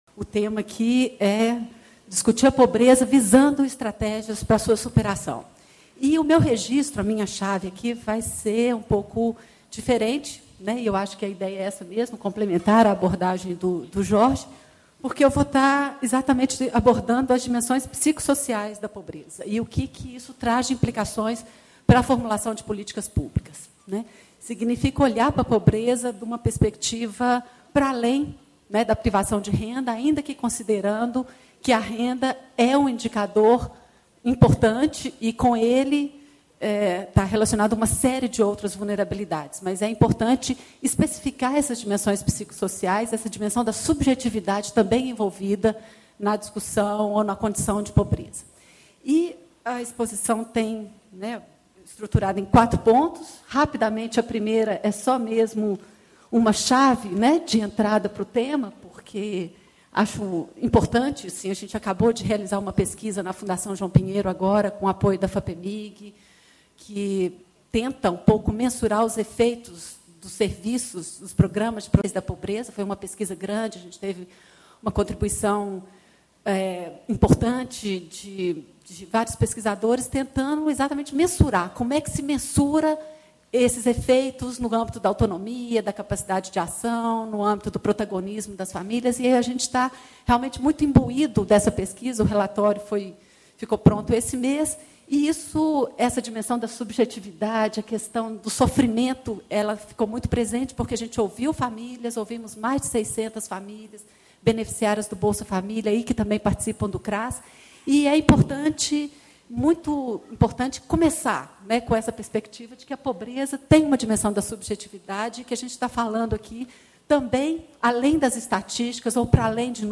Ciclo de Debates Estratégias para Superação da Pobreza - Palestra: O Caráter Multifacetado da Pobreza: Destituição Econômica e Aspectos de Direitos Humanos e Cidadania